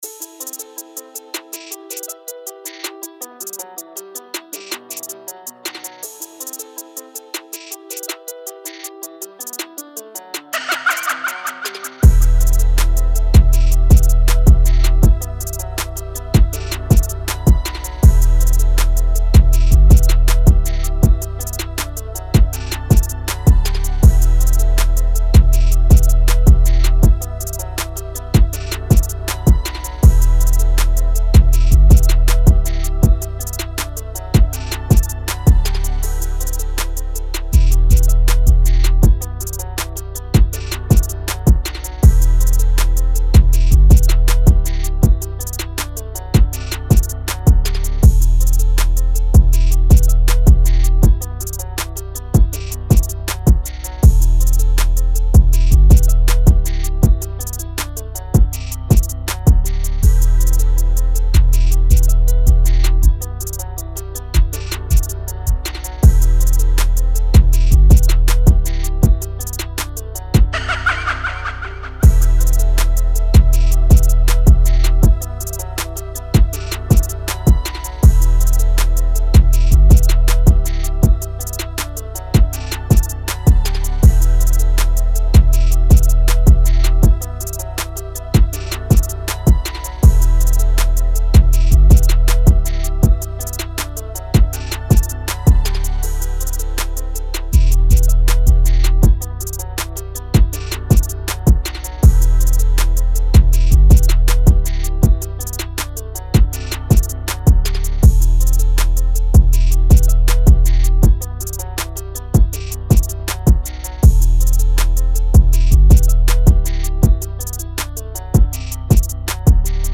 TRAP
E-Min 160-BPM